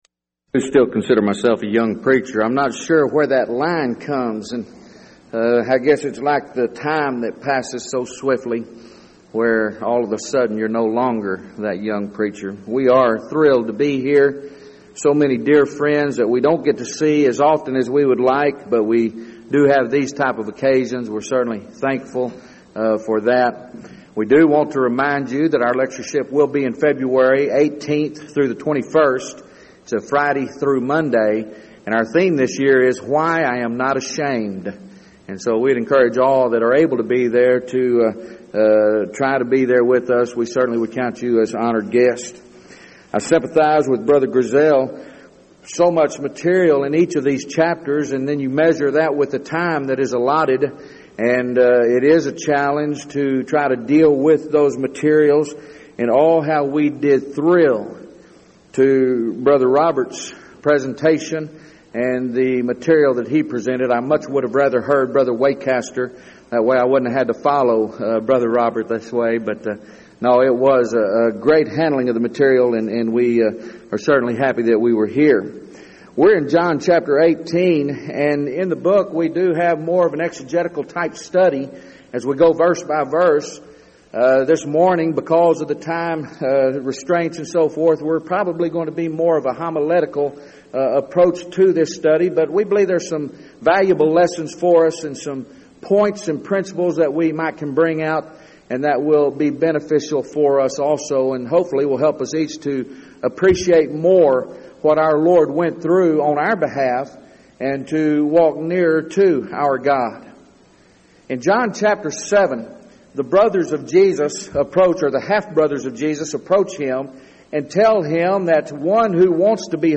Event: 1999 Denton Lectures Theme/Title: Studies in the Book of John